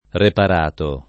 repar#to] pers. m. stor.